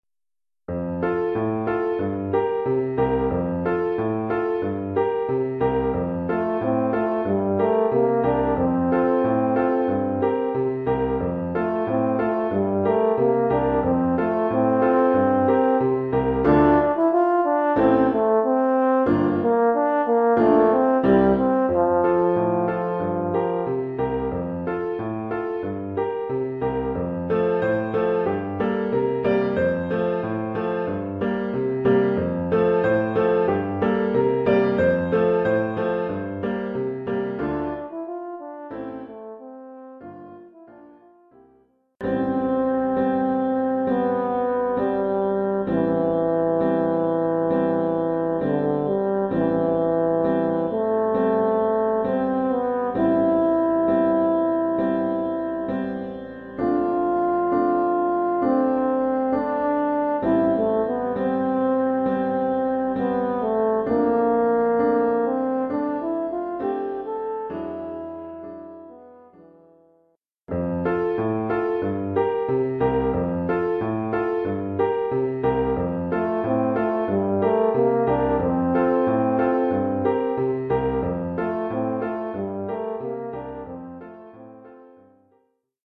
Collection : Saxhorn alto
Oeuvre pour saxhorn alto et piano.